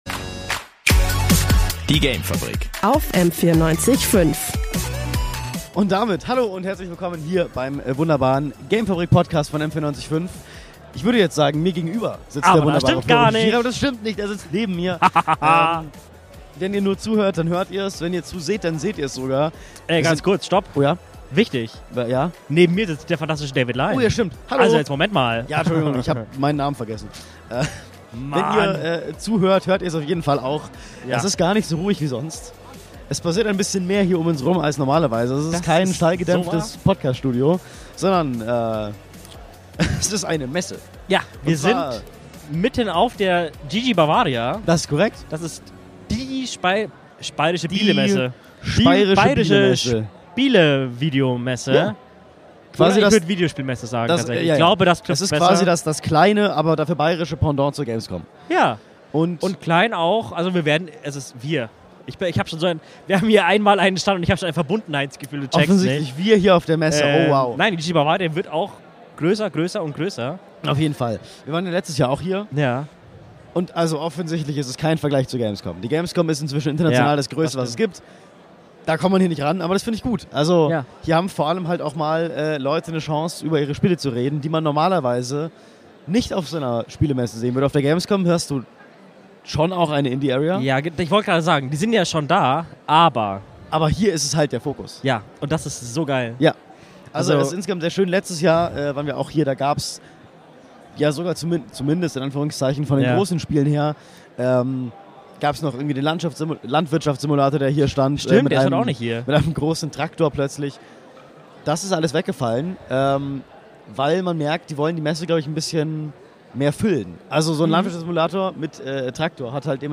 Es ist geschafft, M94.5 hat es irgendwie von der GG Bavaria 2026 zurück geschafft. Drei Tage Messe, drei Tage live, drei Tage Sendung. Und ein kleiner Teil dieser drei Tage hat seinen Weg jetzt hierher geschafft, auf die Podcastplattform eures Vertrauens!...